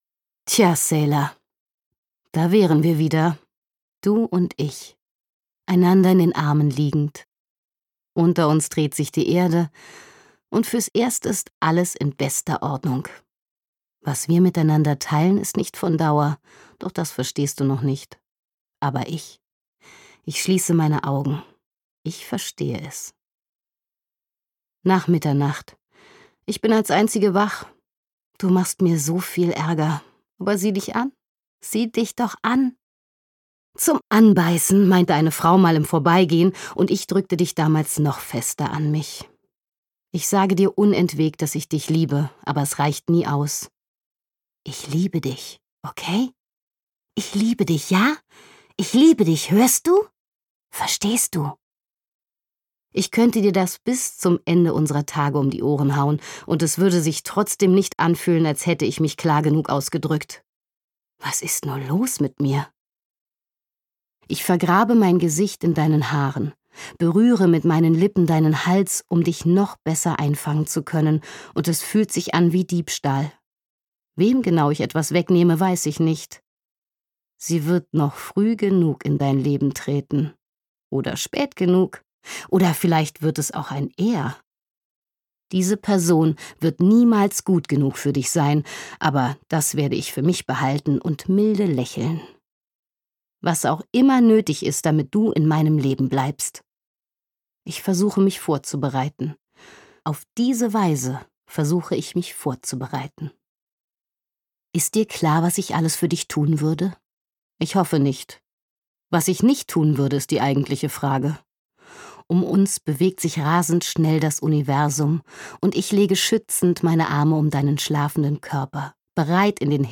Kinderspiel - Claire Kilroy | argon hörbuch
Gekürzt Autorisierte, d.h. von Autor:innen und / oder Verlagen freigegebene, bearbeitete Fassung.